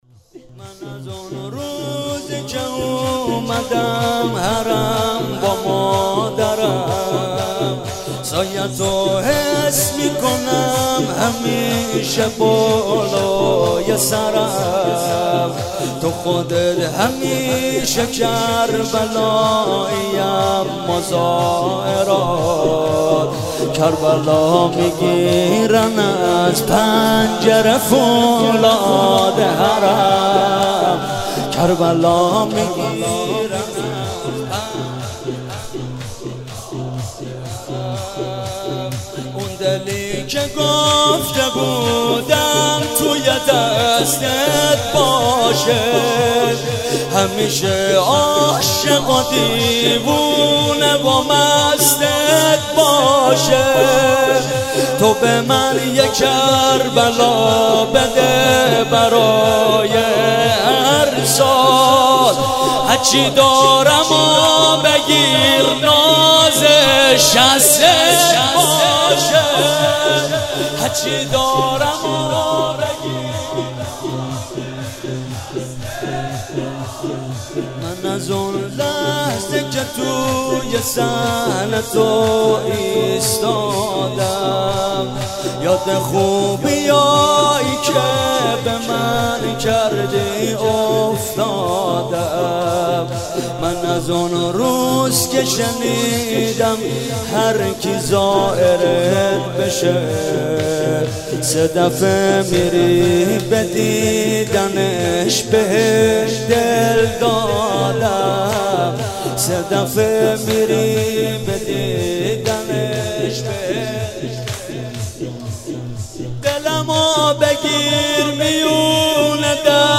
جلسه هفتگی هیئت حسین جان گرگان
شور – من از اون روی که اومدم حرم با مادرم mp3 ۲/۶۴